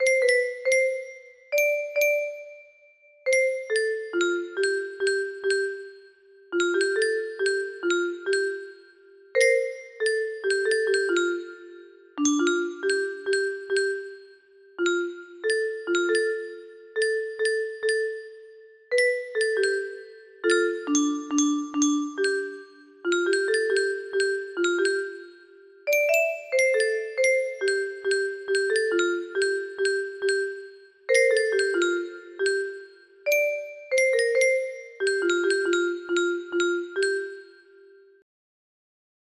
in C major for music boxes